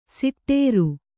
♪ siṭṭēru